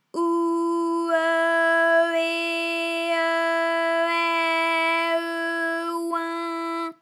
ALYS-DB-001-FRA - First, previously private, UTAU French vocal library of ALYS
ou_eu_eh_eu_ai_eu_oin.wav